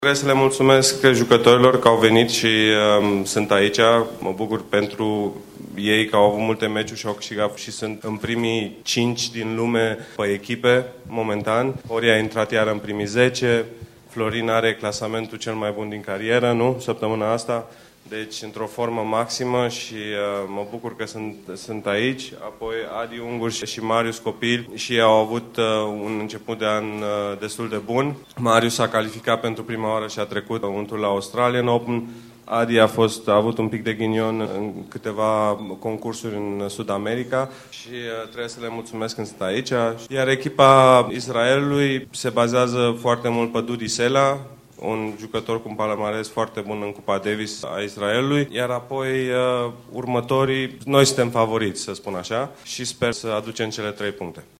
Echipa de Cupa Davis a României a sustinut astăzi conferinţa de presă oficială premergătoare partidei pe care o va susţine la acest final de săptămână la Sibiu contra Israelului.
Declaraţie Andrei Pavel: